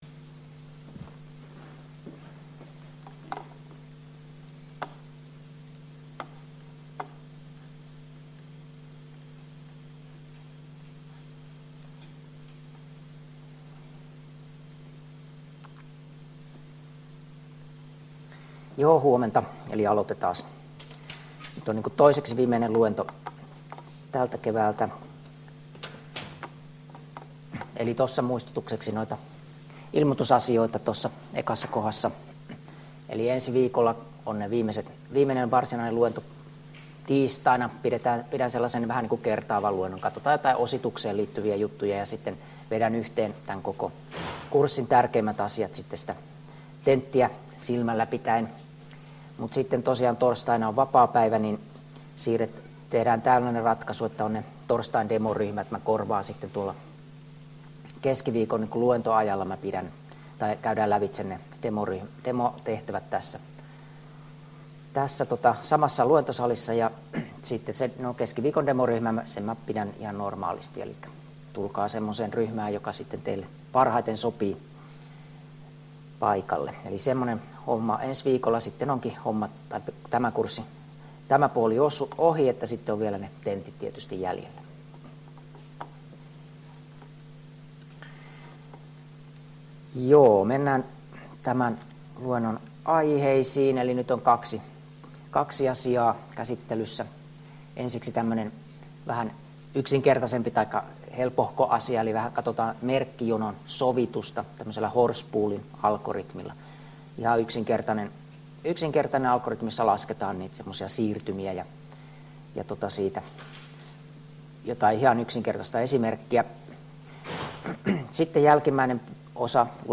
Luento 12 — Moniviestin